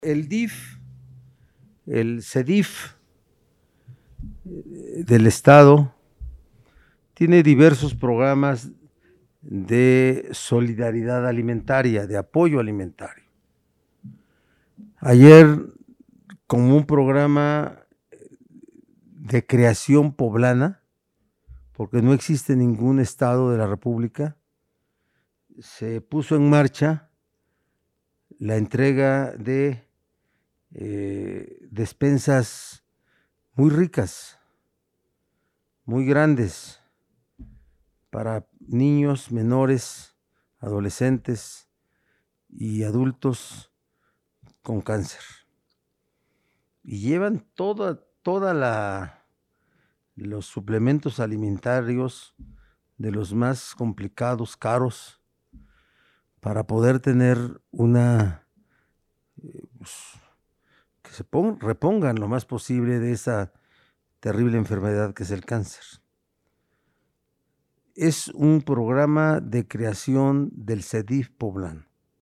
En videoconferencia de prensa en Casa Aguayo, el mandatario recordó que el interés del Gobierno del Estado es apoyar a las y los poblanos en situación de vulnerabilidad, por lo que con el programa alimentario, buscan que las personas que padecen cáncer puedan enfrentar mejor los efectos secundarios de los tratamientos como las quimioterapias.